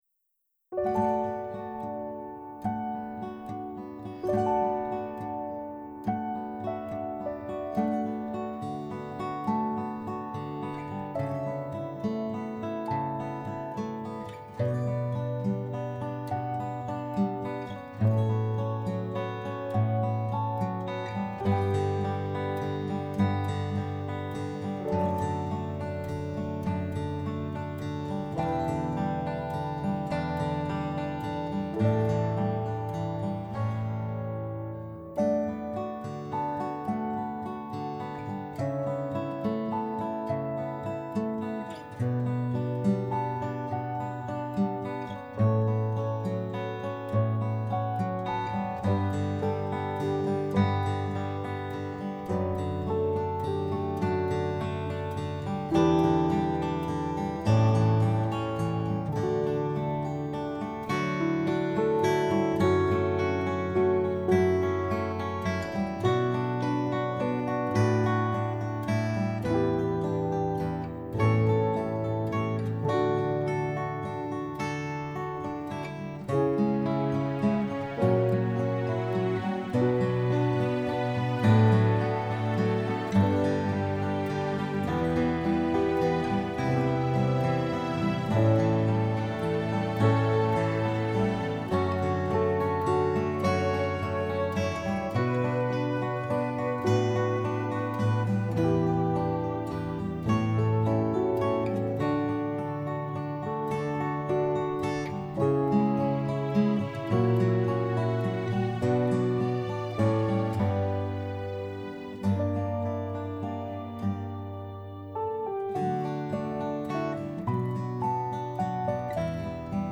I decided my song would benefit from a slower tempo so the guitar finger picking could be appreciated.
When I finished editing the lower guitar tracks, I recorded another, higher guitar part.
Below, I share some other instrumental versions of my song that I will eventually share on Insight Timer.
someone-to-love-you-arrangement-2-5-20.mp3